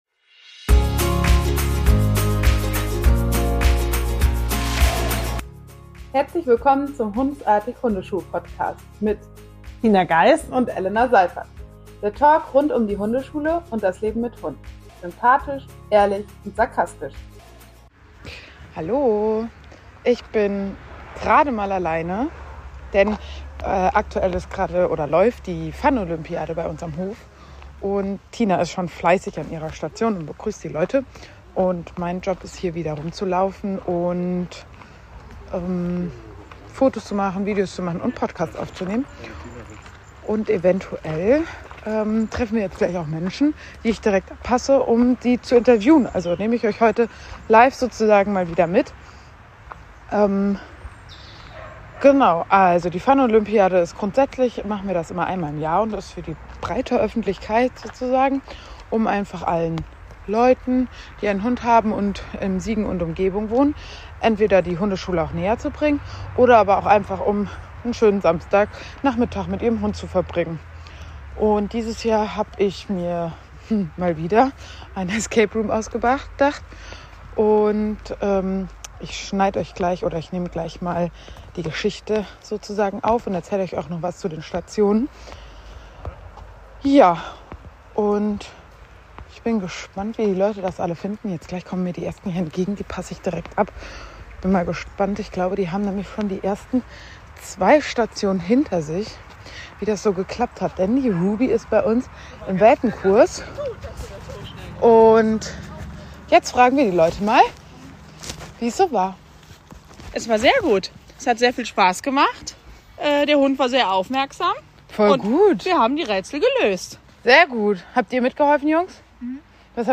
Im Mai fand unsere Funolympiade statt, heute nehmen wir euch Live an diesem Tag mit.